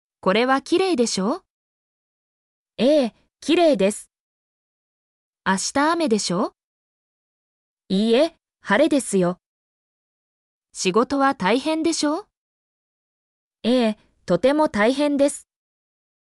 mp3-output-ttsfreedotcom-3_ypzJC136.mp3